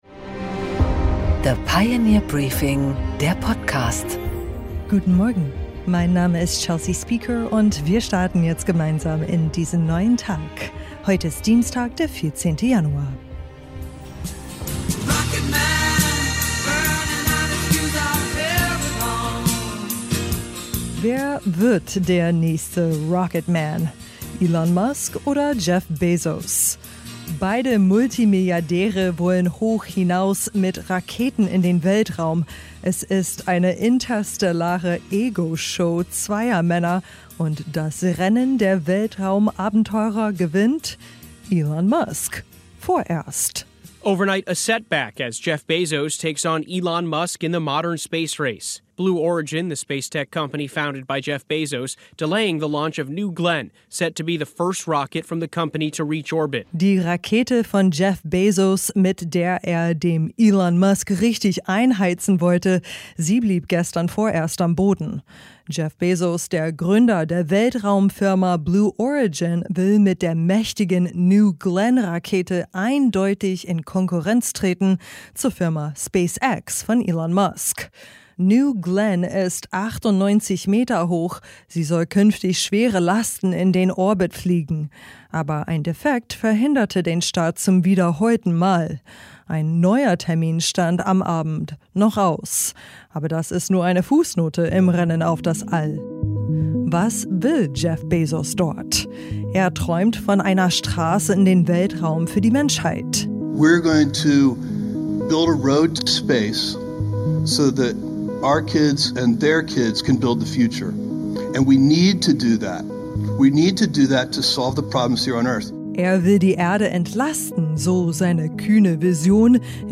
Interview mit Lars Klingbeil